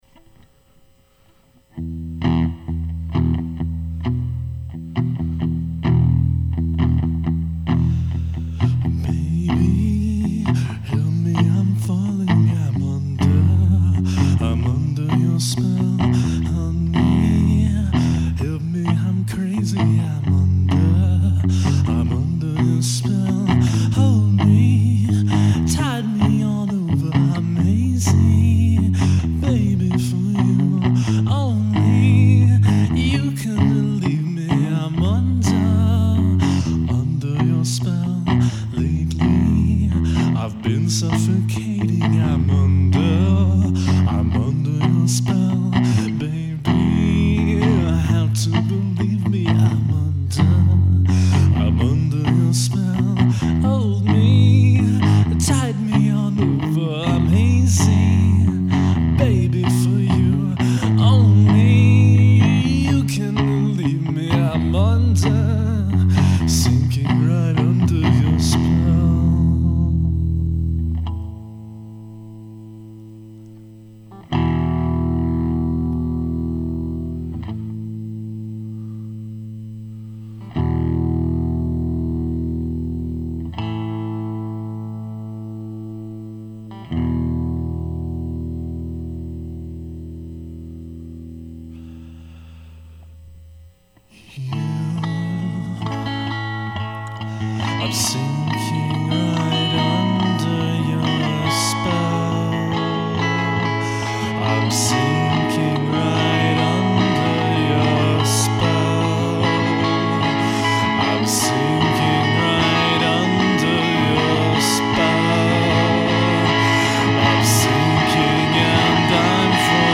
Yesterday I was screwing around the guitar and came up with a nice little chord sequence.
I like the sound on the guitar; pleasantly buzzy!)
Works surprisingly well without percussion.
A catchy jam.
I also like the vibrato on the vocals.